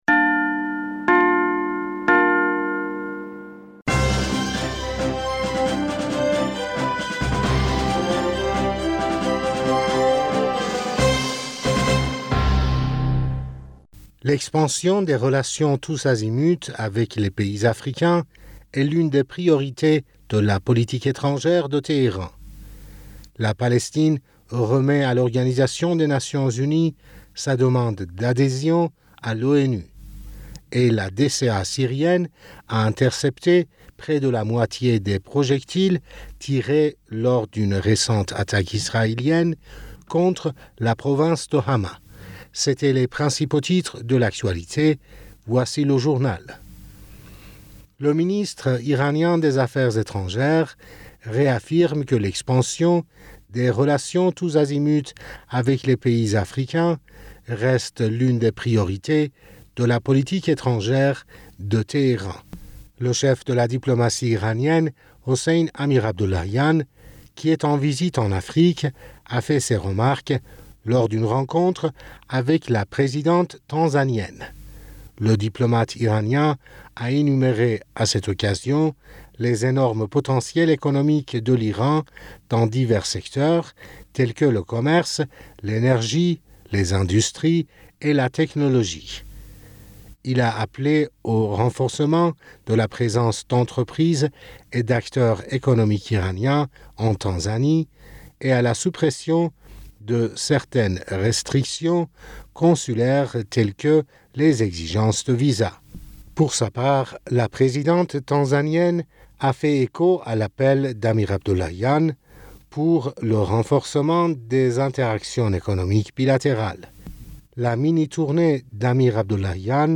Bulletin d'information Du 27 Aoùt